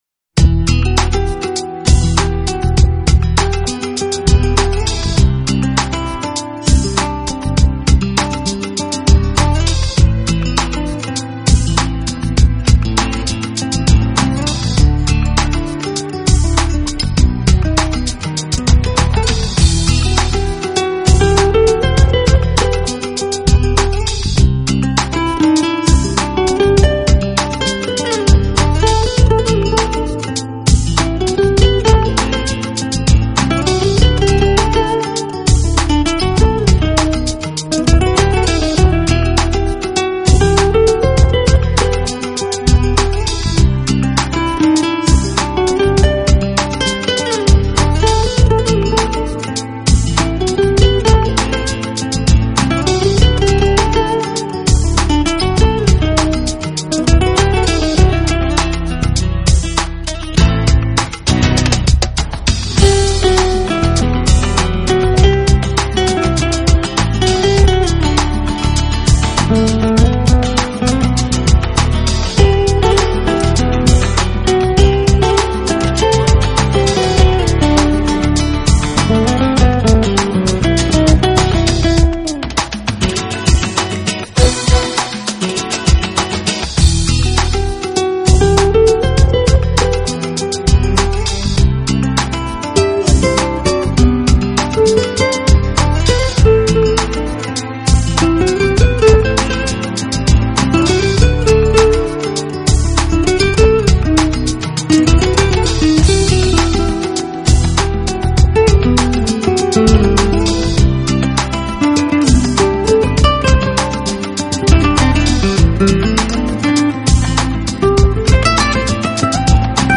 【爵士吉他】
融合了古典与拉丁，世界音乐元素